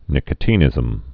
(nĭkə-tē-nĭzəm)